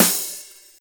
drum-hitnormal2.wav